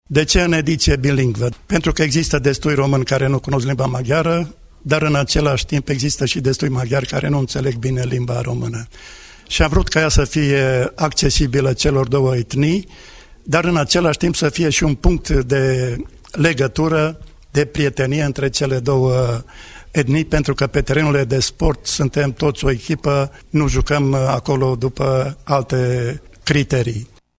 Evenimentul a avut loc la Biblioteca Județeană Mureș și a reunit foști și actuali sportivi, antrenori, conducători de cluburi, jurnaliști și iubitori ai sportului.